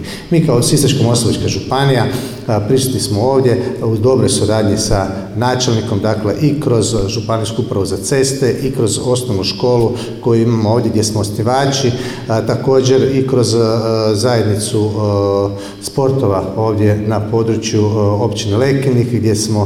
Zamjenik župana Mihael Jurić je, kao izaslanik predsjednika Hrvatskog sabora Gordana Jandrokovića i predsjednika Vlade Republike Hrvatske Andreja Plenkovića, nazočio je programu obilježbe Dana općine Lekenik i blagdana Svetog Jurja. a